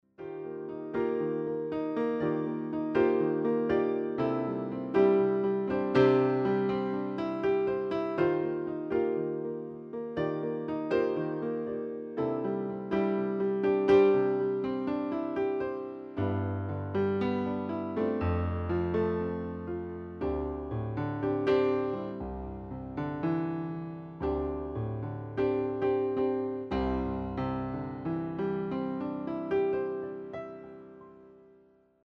Ik wilde al een tijdje een gewone, simpele, niet te dure, elektrische piano hebben.
Stukje op de piano gespeeld:
Piano-Yamaha1.mp3